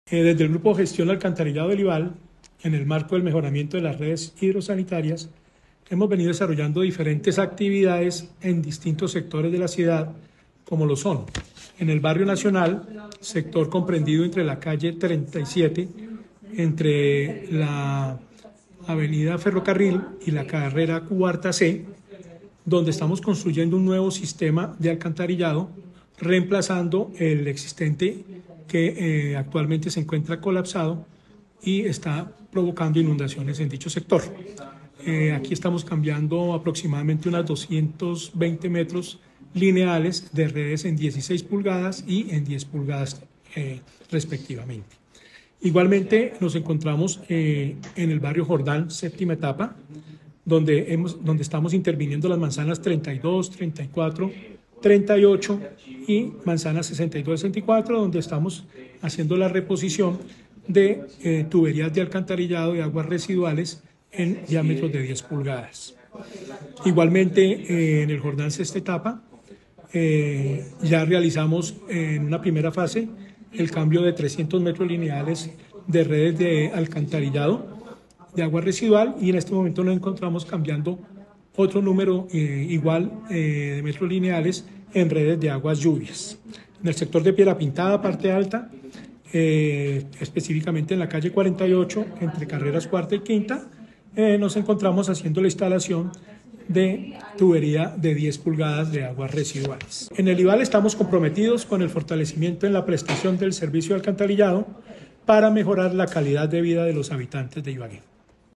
Transcripción comunicado de prensa acciones Alcantarillado